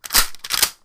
shotgun_pump_0.wav